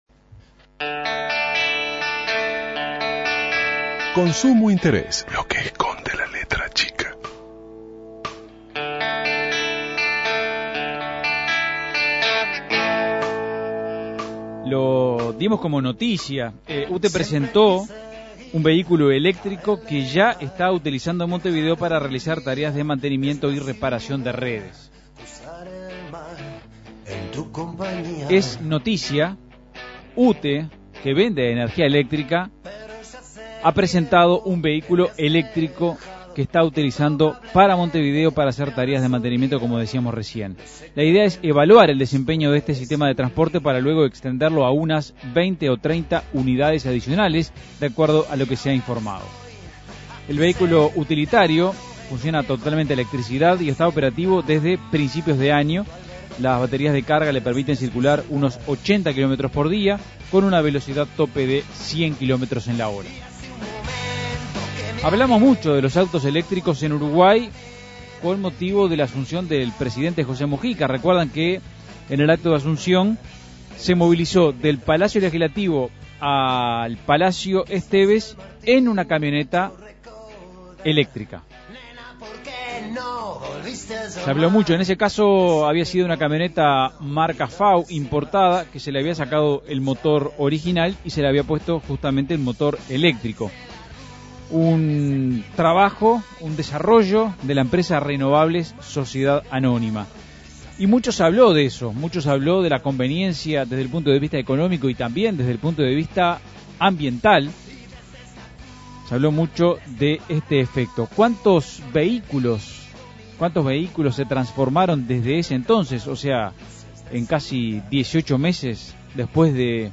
Entrevista a Ing. Gonzalo Casaravilla, presidente de UTE.